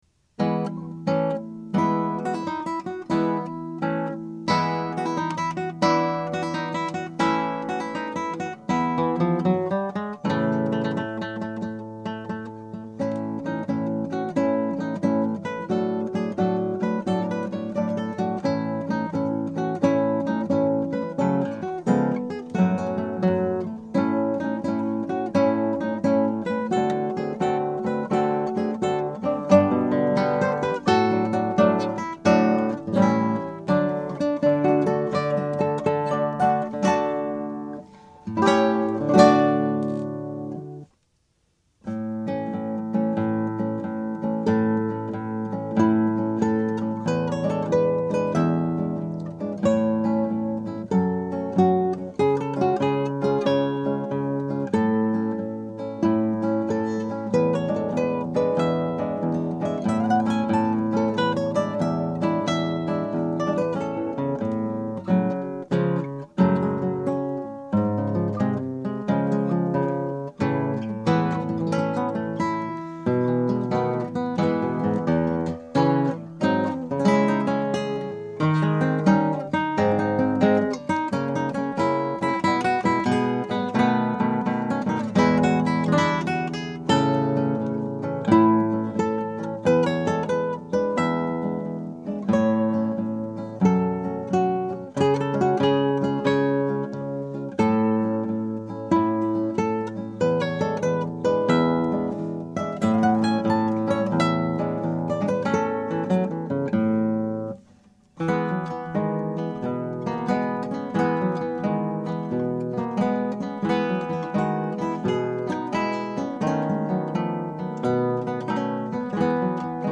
Scraps from the Operas arranged for Two Guitars
Scrap: Tempo di Marcia.